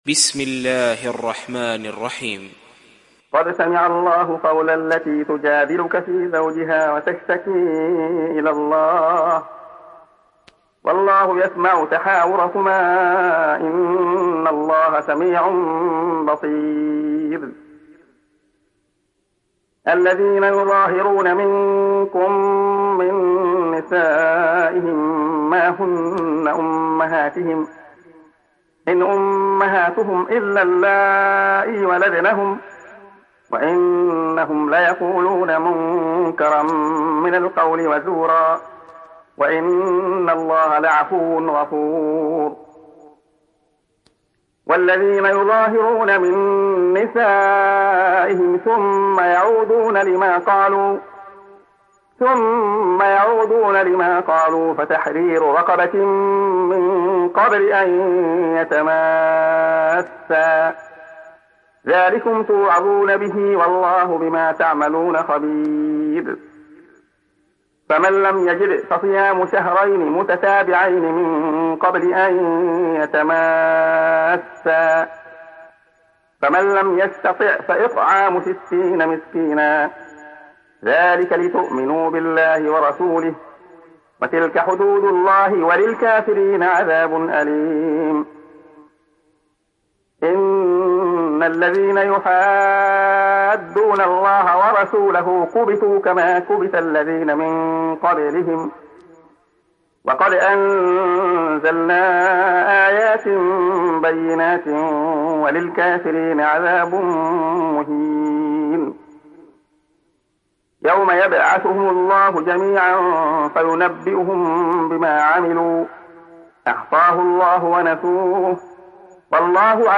Mücadele Suresi İndir mp3 Abdullah Khayyat Riwayat Hafs an Asim, Kurani indirin ve mp3 tam doğrudan bağlantılar dinle